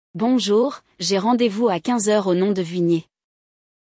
Le dialogue (O diálogo)